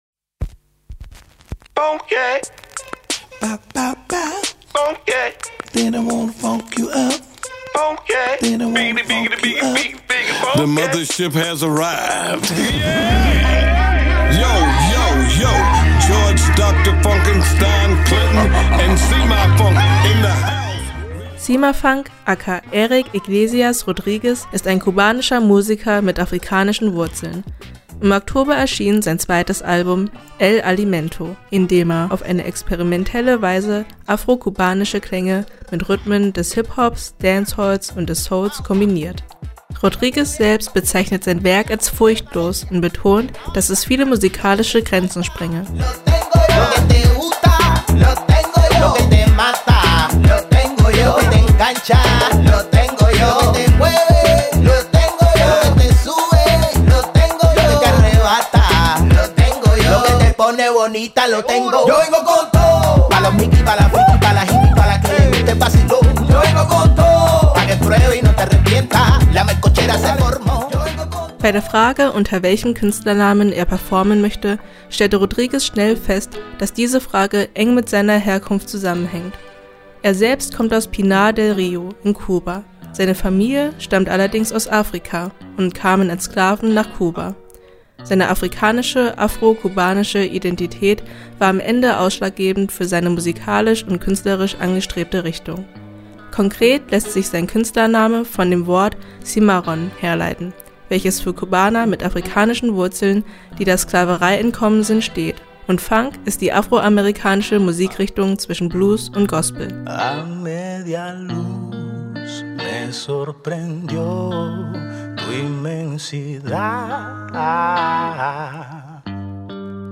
Tiefgehender Bass, eingängige Beats und schematische Vocals